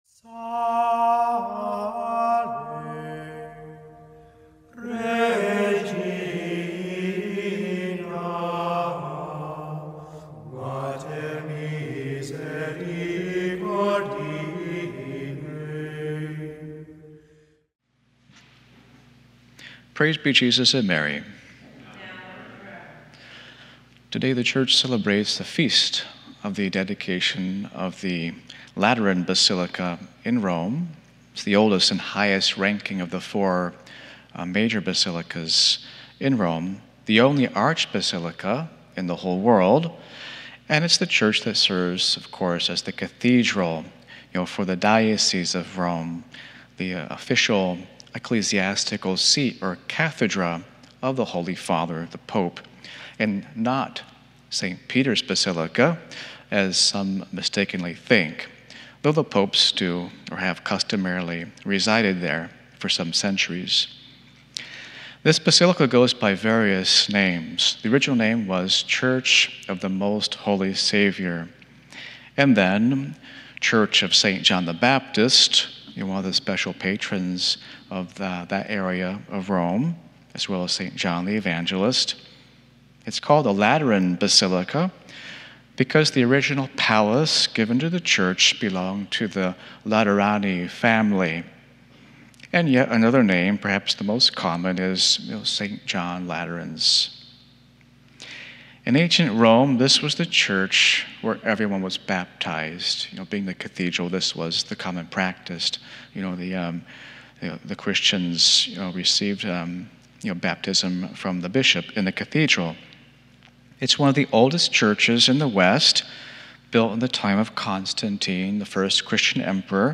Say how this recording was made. Mass: Dedication of the Lateran